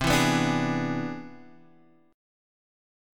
C+M9 chord